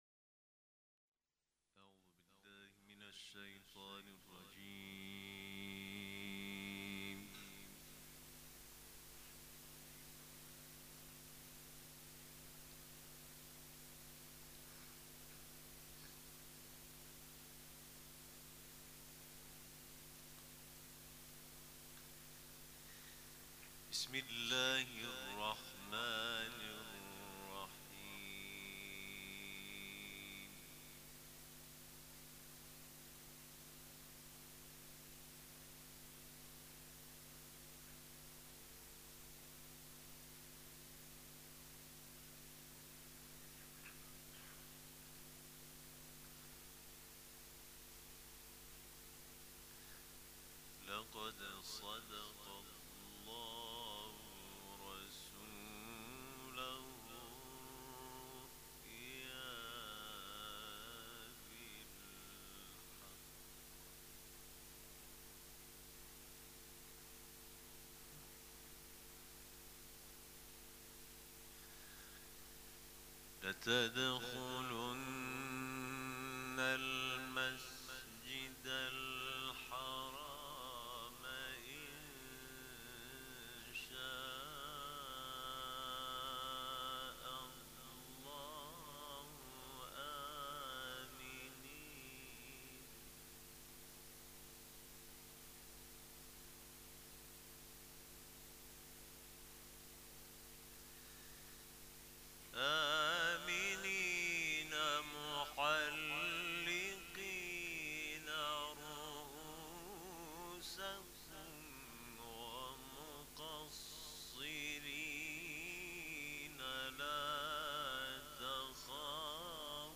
قرائت قرآن
ولادت حضرت محمد (ص) و امام صادق (ع)